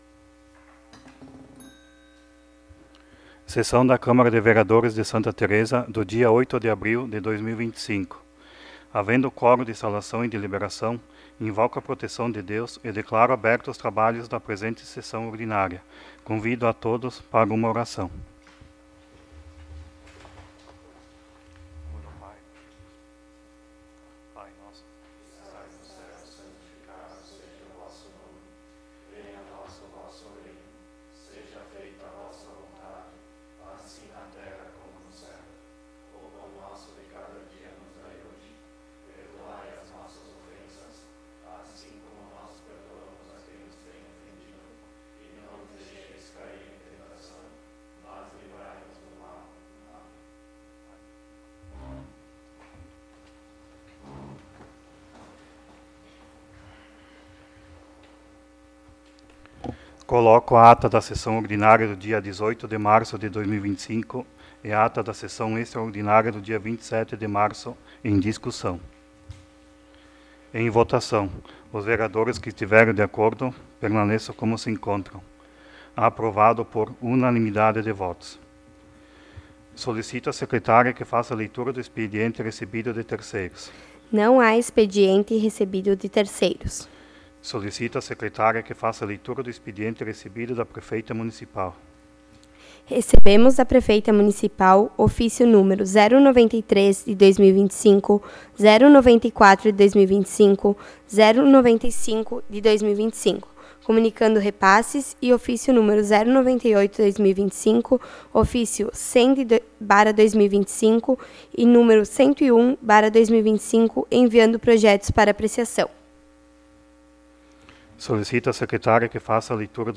05° Sessão Ordinária de 2025